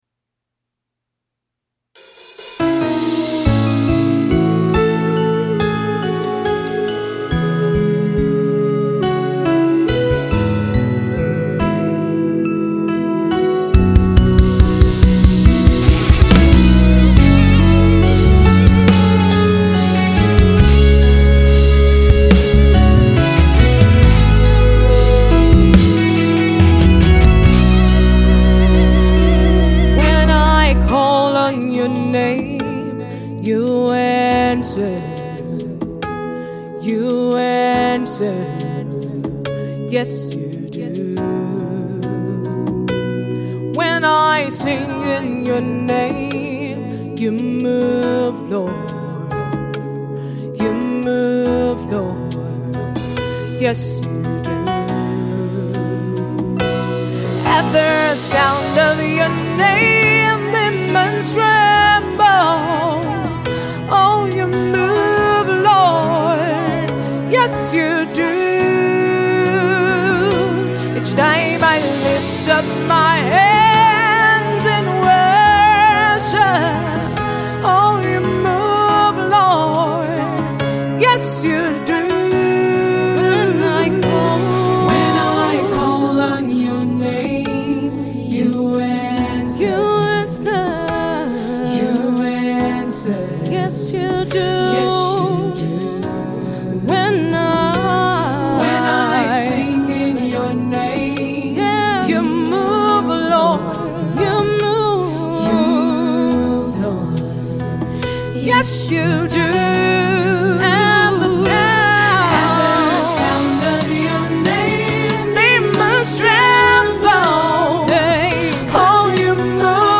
spirit-filled song